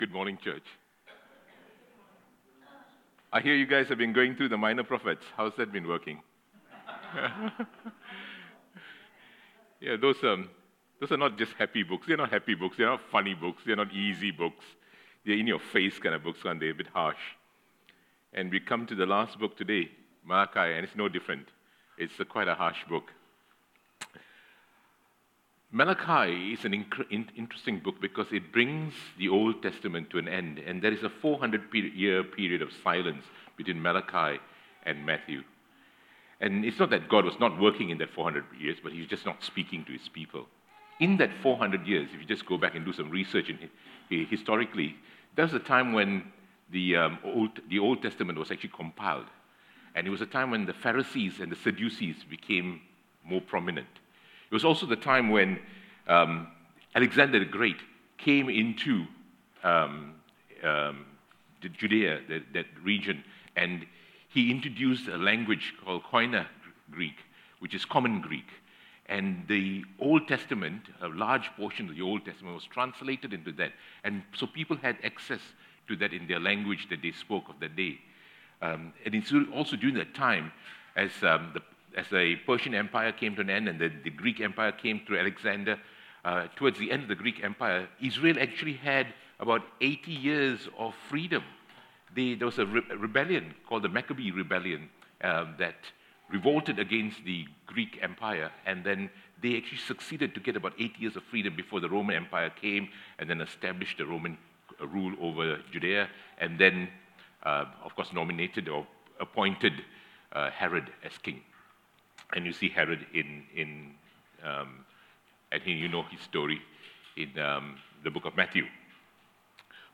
Sermons | Coolbellup Community Church